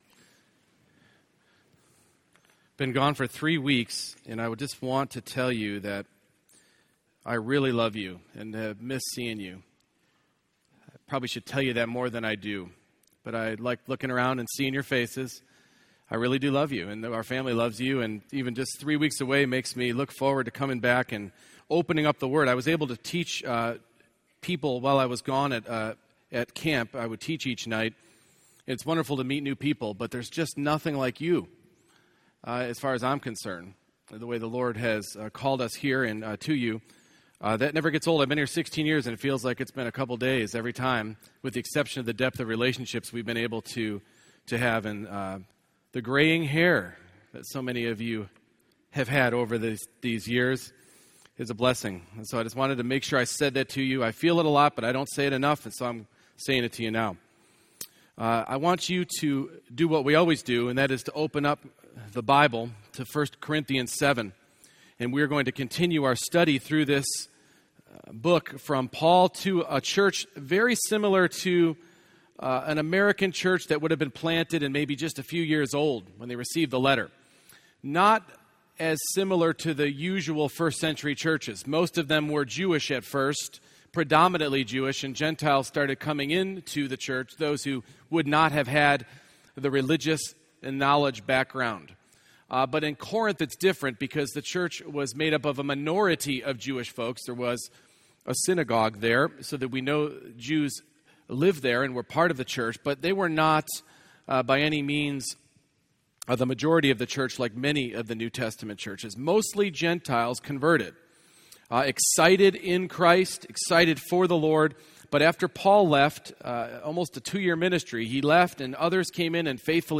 1 Corinthians 7:17-24 Service Type: Morning Worship Live out the life assignment the Lord has given you.